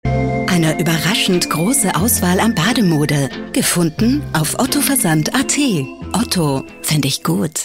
stimmprobe werbesprecherin . sprecher werbung . werbespots . tv spots . radiospots
OTTO/TV-spot AT/mp3